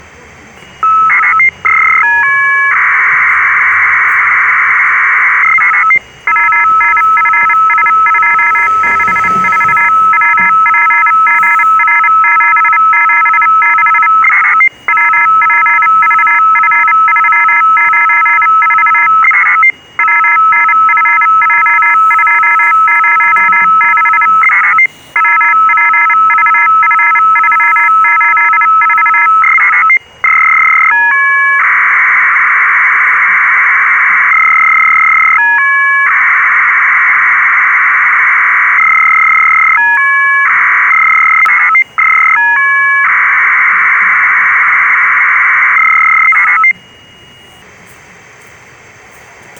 AFSK_POCSAG_AND_MORSErawrawrawbiatch.wav